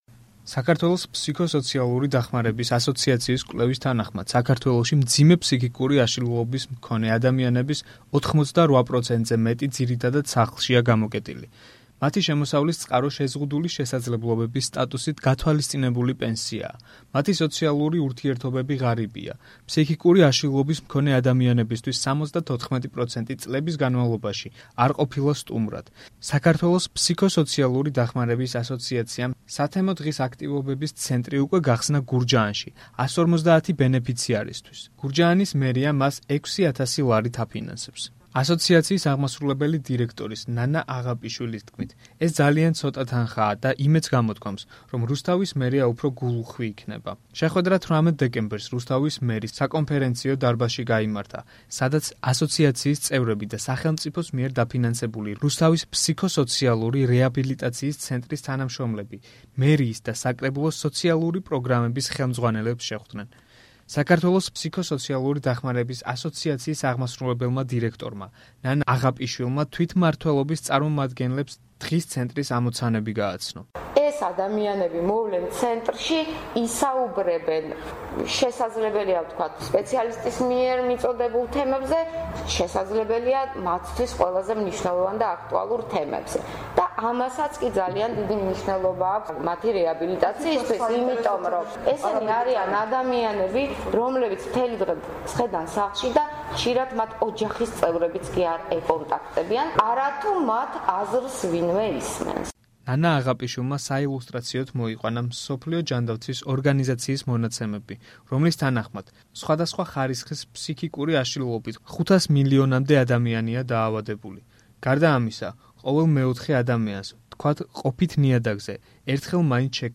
შეხვედრა 18 დეკემბერს რუსთავის მერიის საკონფერენციო დარბაზში გაიმართა, სადაც ასოციაციის წევრები და სახელმწიფოს მიერ დაფინანსებული რუსთავის ფსიქოსოციალური რეაბილიტაციის ცენტრის თანამშრომლები მერიისა და საკრებულოს სოციალური პროგრამების ხელმძღვანელებს შეხვდნენ.